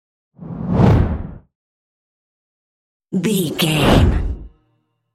Whoosh deep fast x2
Sound Effects
Fast
whoosh